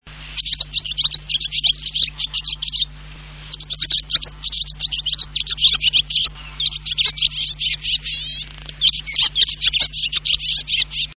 Jaskółka dymówka - Hirundo rustica
głosy